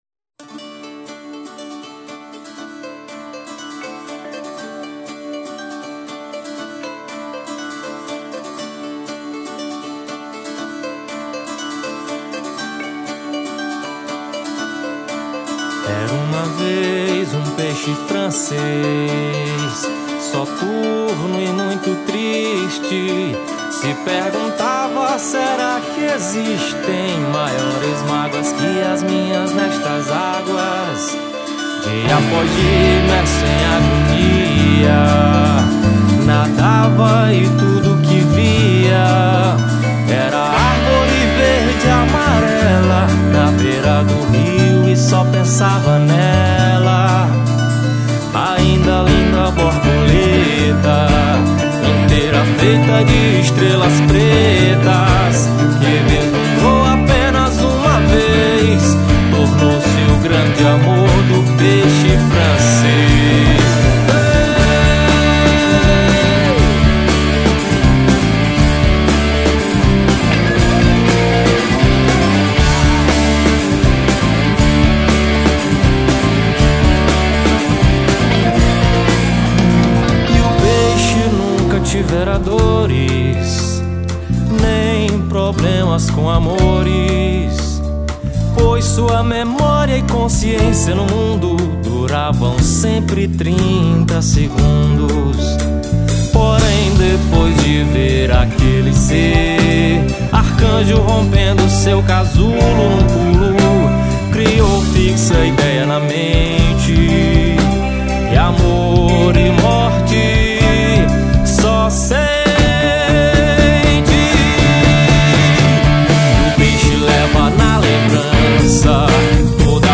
1919   04:47:00   Faixa:     Rock Nacional